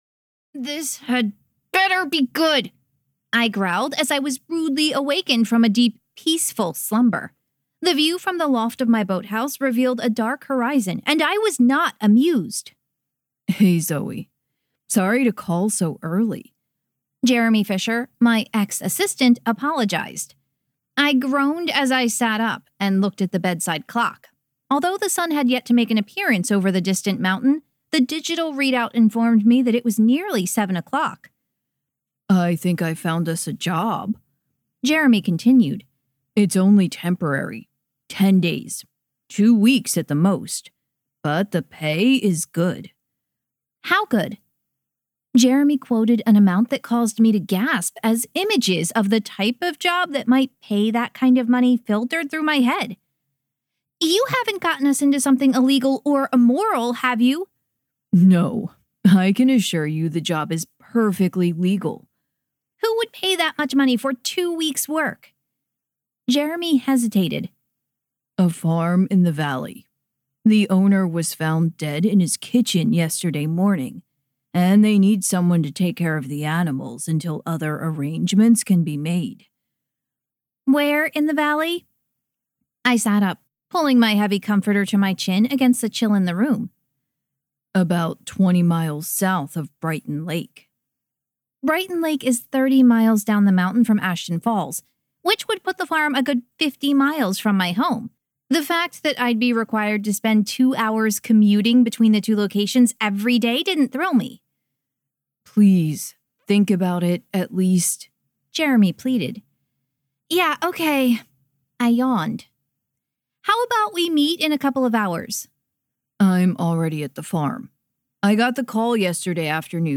• Audiobook
Book 2 Retail Audio Sample The Trouble with Turkeys (Zoe Donovan Mystery).mp3